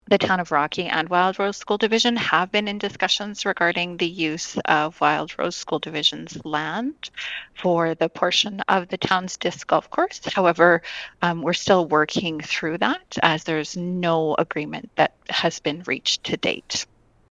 In an interview with 94.5 Rewind Radio